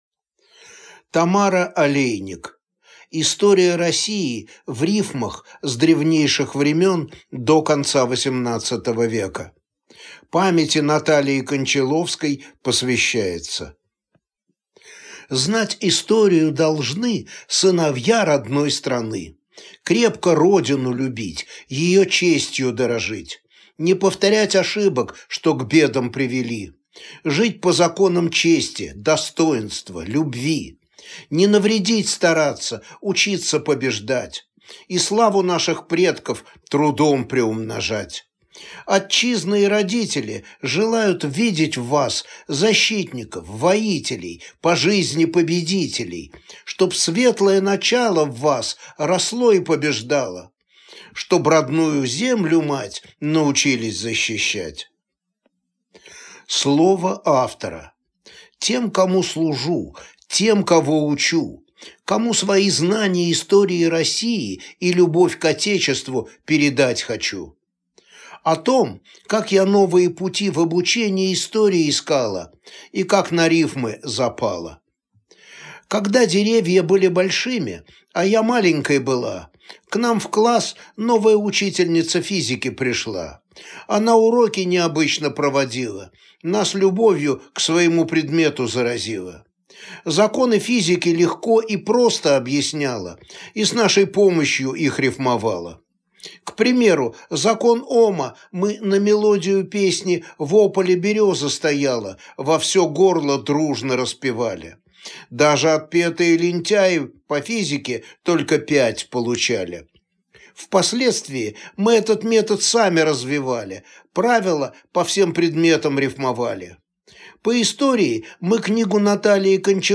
Аудиокнига История России в рифмах с древнейших времен до конца XVIII века | Библиотека аудиокниг